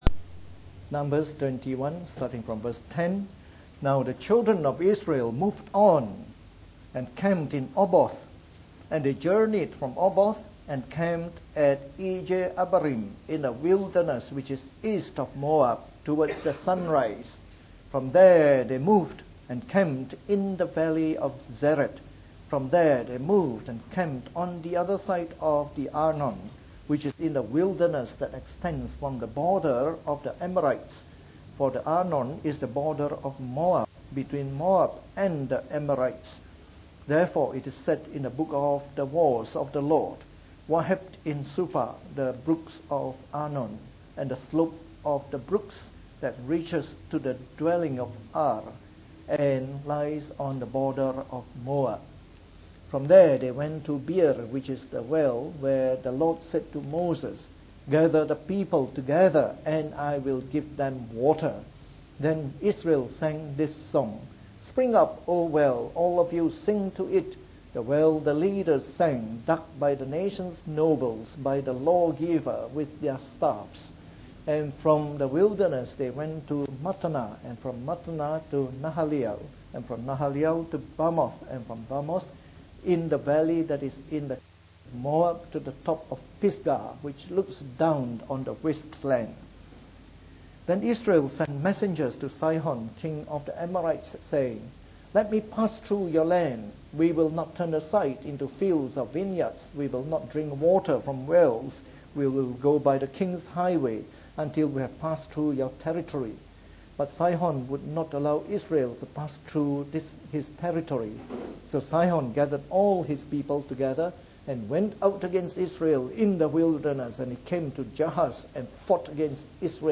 From our series on the “Book of Numbers” delivered in the Morning Service.